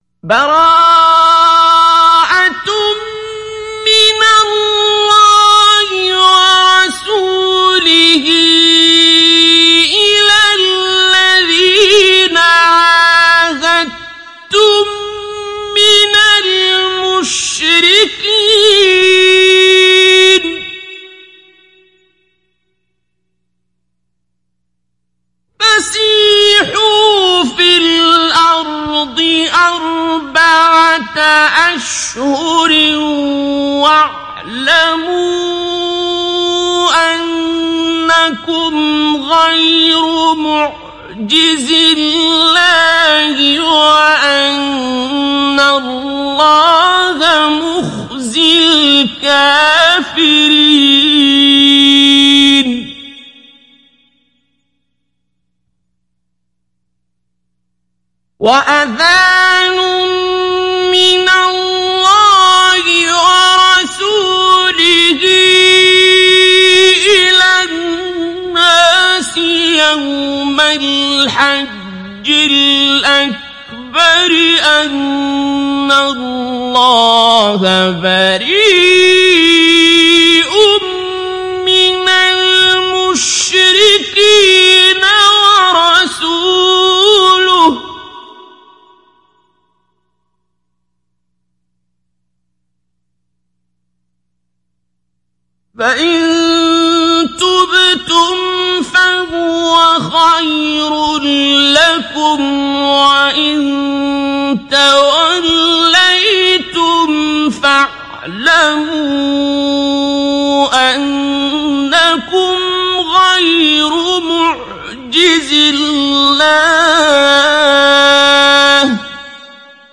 Download Surah At Tawbah Abdul Basit Abd Alsamad Mujawwad